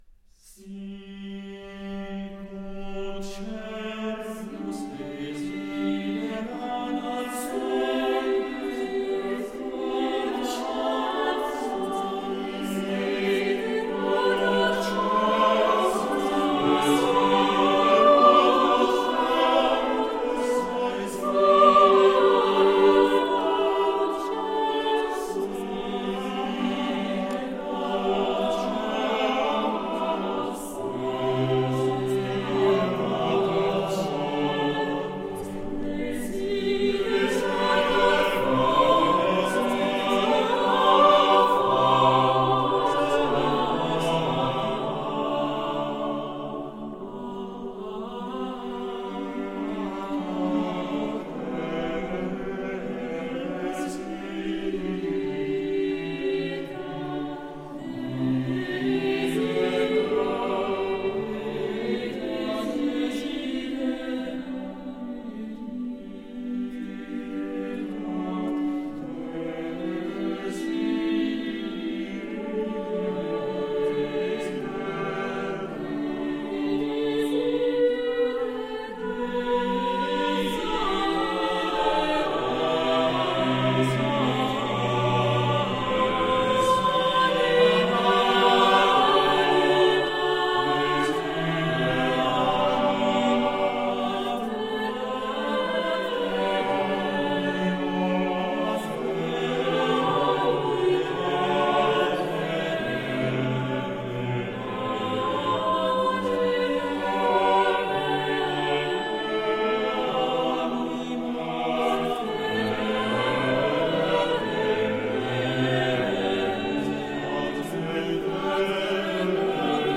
Palestrina, «Sicut cervus» mottetto partitura